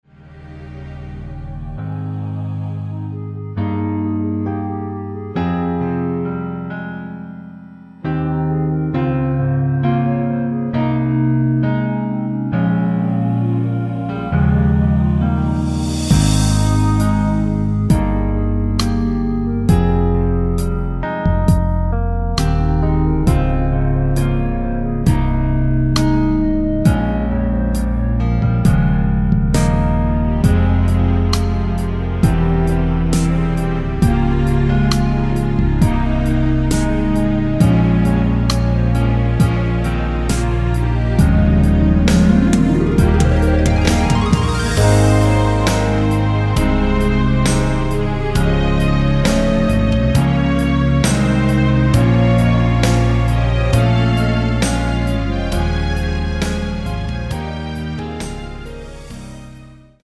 -4)멜로디 MR (-4)내린 멜로디 포함된 MR 입니다
Ab
노래방에서 노래를 부르실때 노래 부분에 가이드 멜로디가 따라 나와서
앞부분30초, 뒷부분30초씩 편집해서 올려 드리고 있습니다.
중간에 음이 끈어지고 다시 나오는 이유는